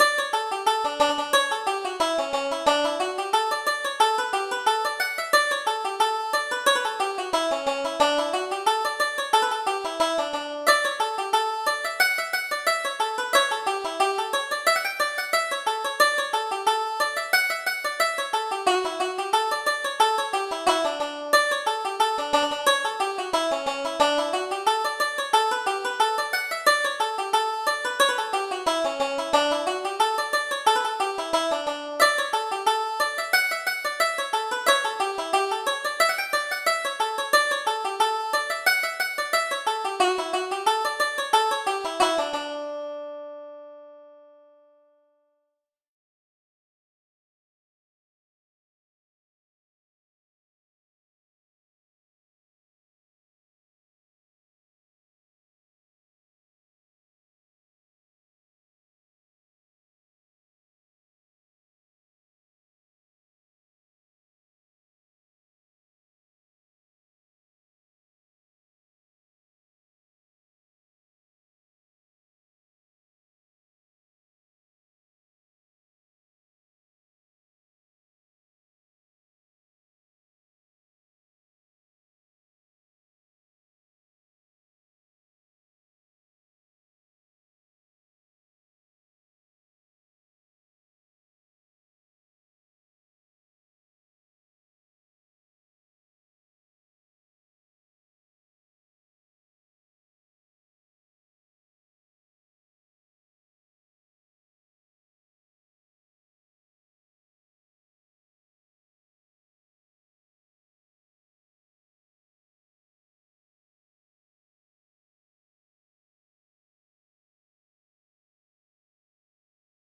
Reel: The Broken Pledge